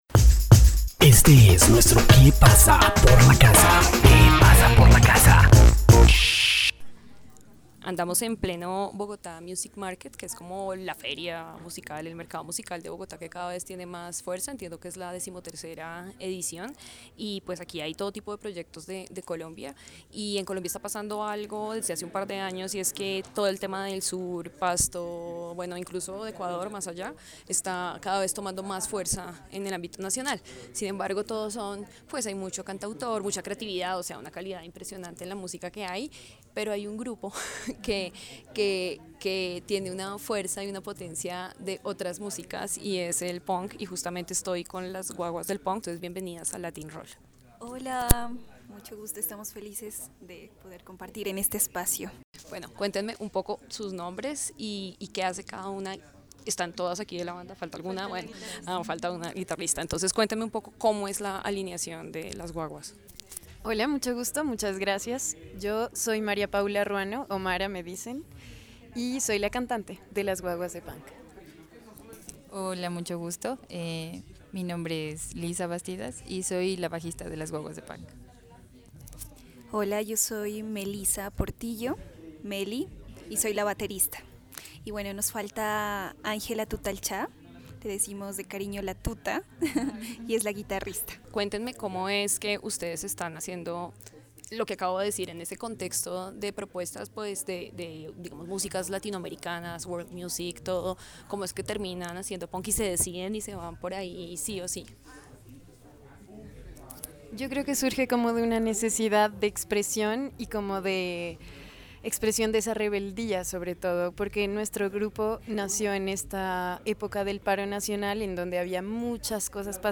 Hablamos con este grupo de mujeres de Pasto, Colombia, en el Bogotá Music Market BOmm, una ciudad que actualmente tiene una fuerte presencia con sus artistas en los escenarios musicales de habla hispana.
Latin-Roll - Entrevistas
las-guaguas-de-pank-punk-del-sur-de-colombia.mp3